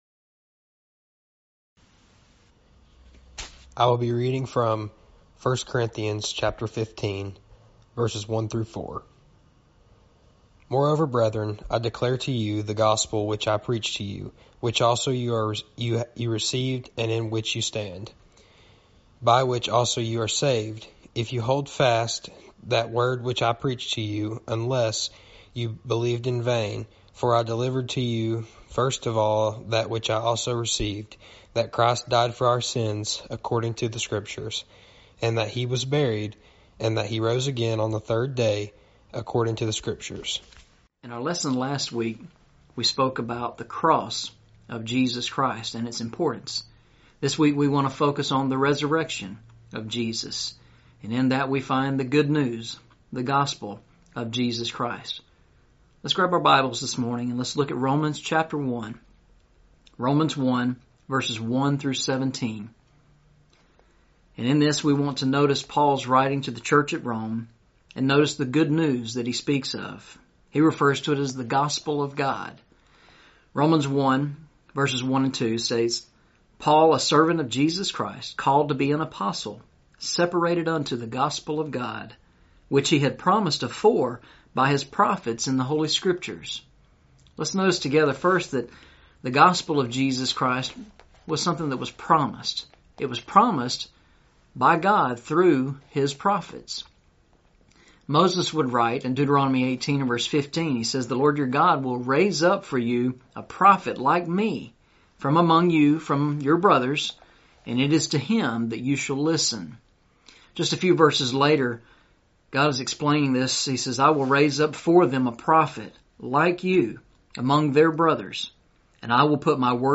Eastside Sermons Service Type: Sunday Morning Preacher